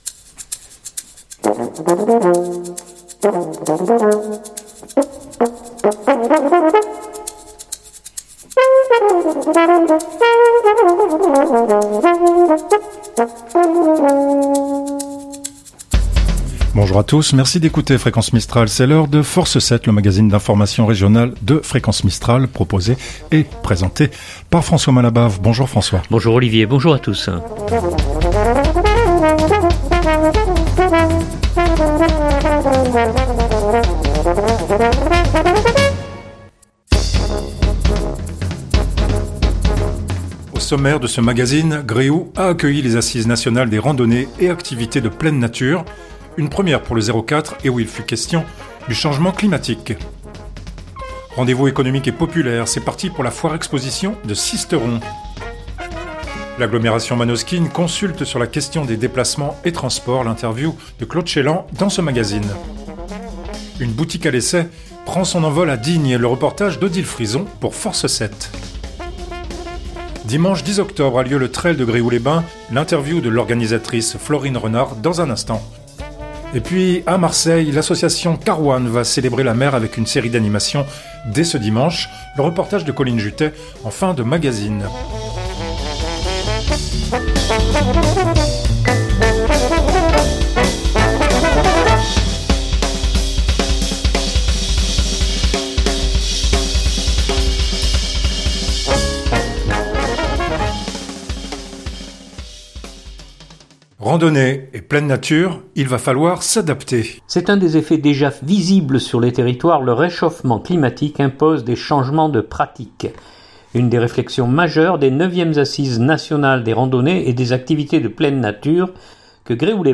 un magazine d’information régional